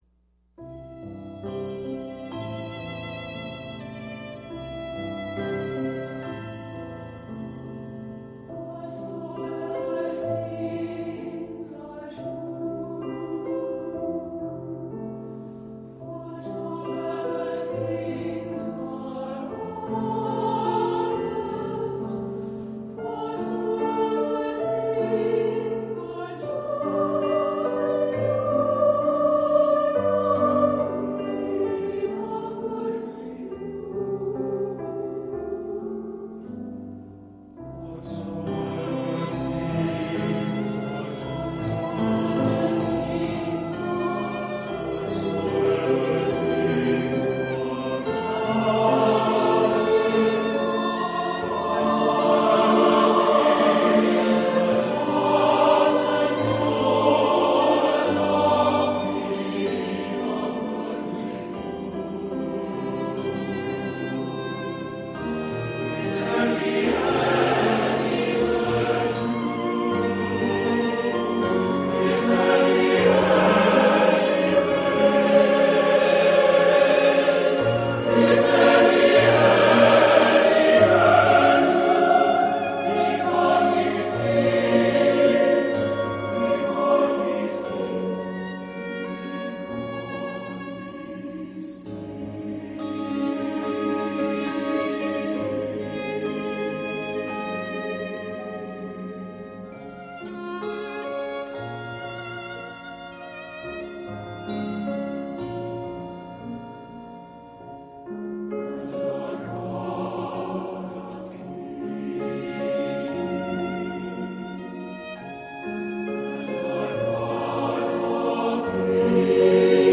8-9 scored for SATB choir, piano, optional violin
and handbells